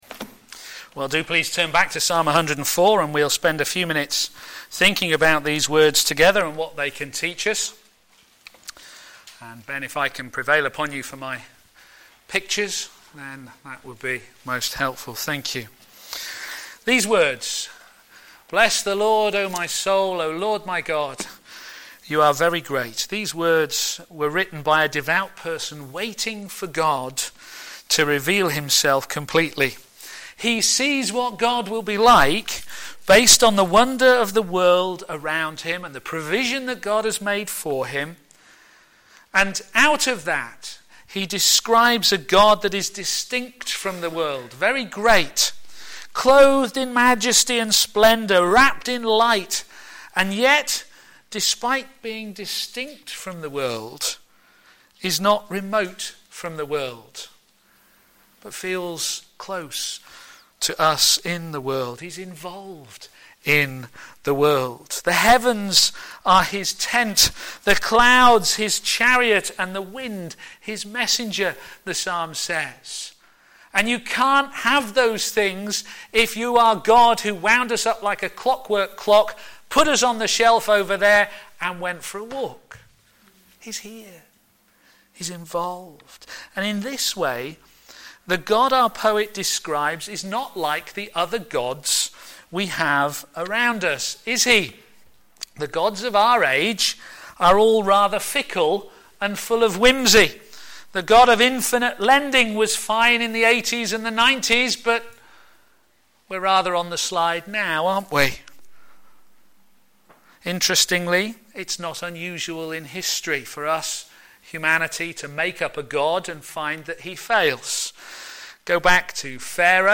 a.m. Service
Harvest Sermon